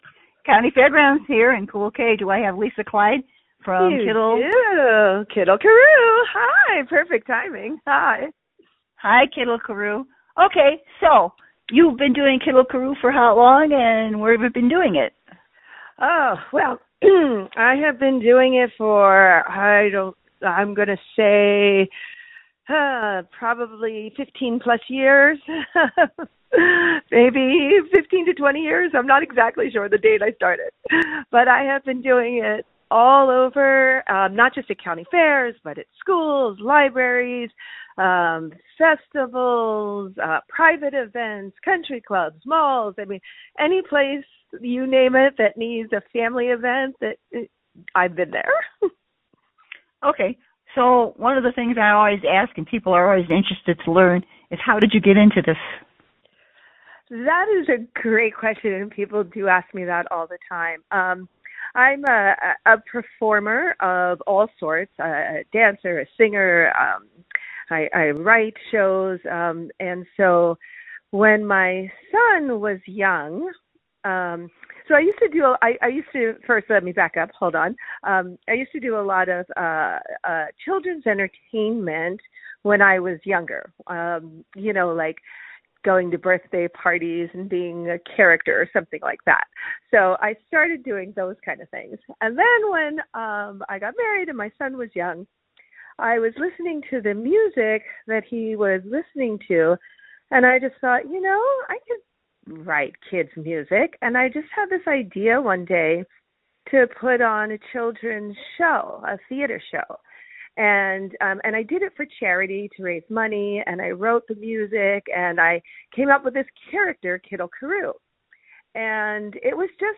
Kiddle Karoo – interview